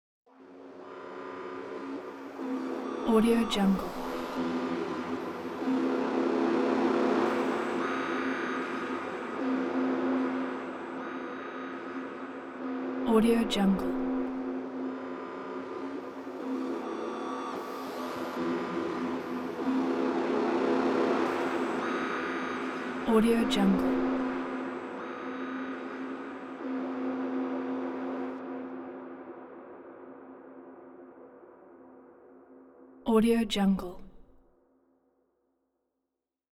دانلود افکت صدای نفس کشیدن غول
افکت صدای نفس کشیدن غول یک گزینه عالی برای هر پروژه ای است که به صداهای بازی و جنبه های دیگر مانند تنش، اضطراب و شوم نیاز دارد.
Sample rate 16-Bit Stereo, 44.1 kHz
Looped No